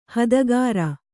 ♪ hadagāra